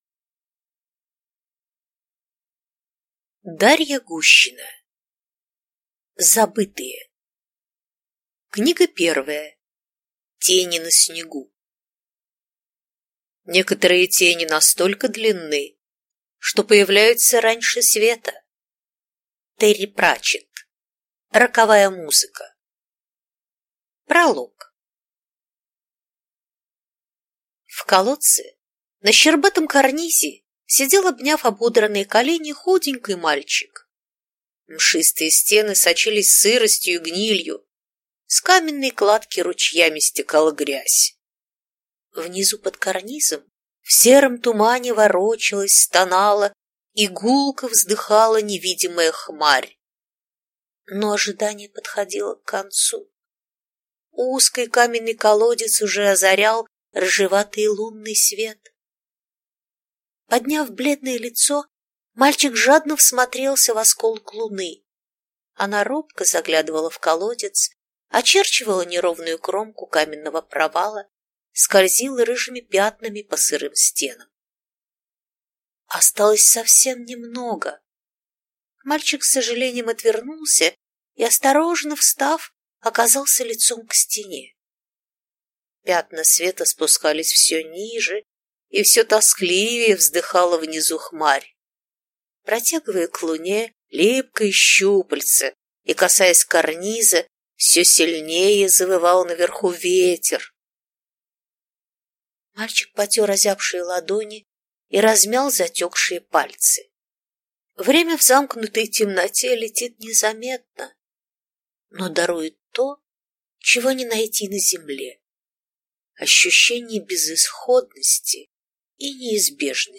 Аудиокнига Забытые: Тени на снегу | Библиотека аудиокниг